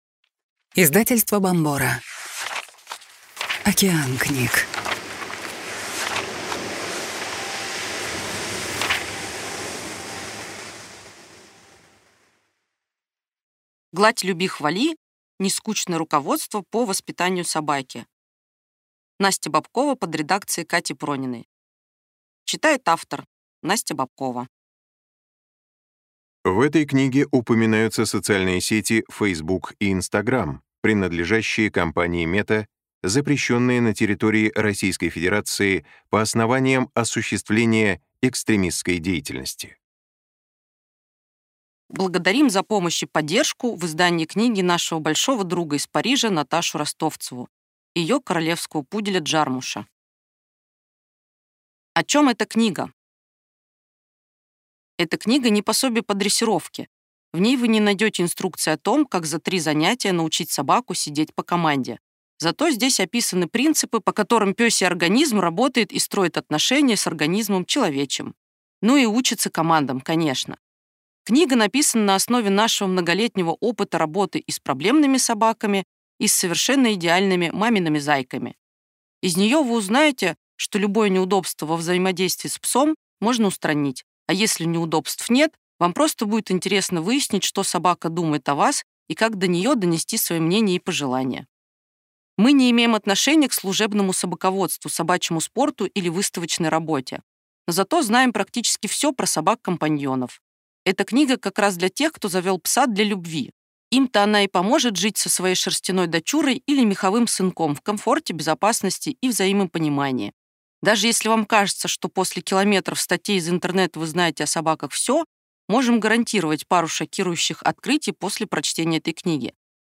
Аудиокнига Гладь, люби, хвали. Нескучное руководство по воспитанию собаки | Библиотека аудиокниг